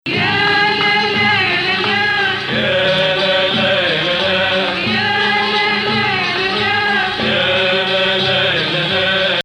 Bayati 3